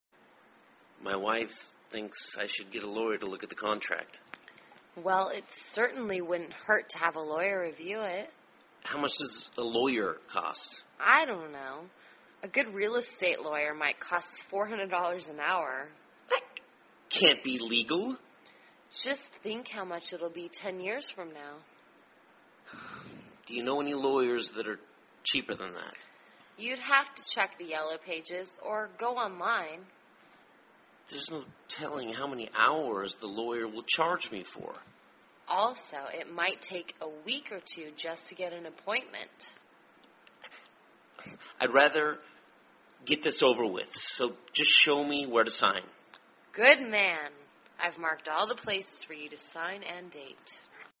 卖房英语对话-Signing Contract With Realtor(1) 听力文件下载—在线英语听力室